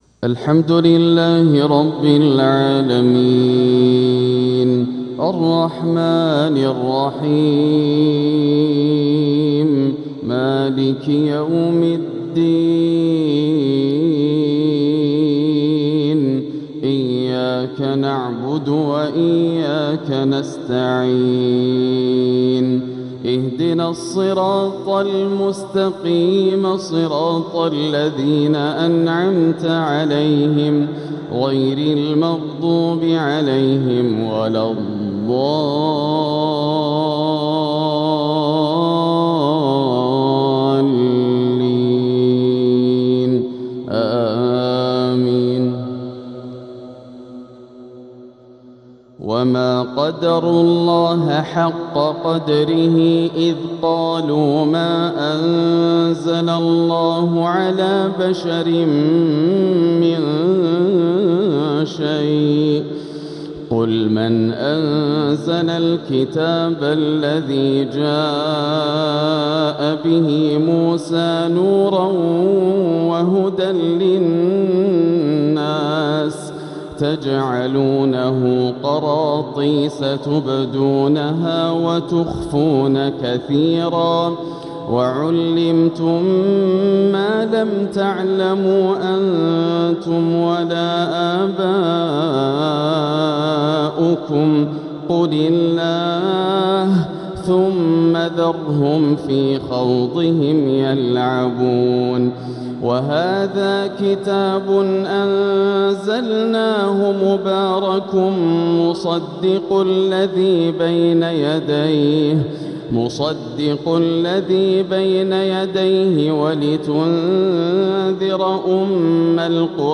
ترنُّم عذب يأسر اللُّب | تلاوة من سورة الأنعام | فجر الأحد 9-2-1447هـ > عام 1447 > الفروض - تلاوات ياسر الدوسري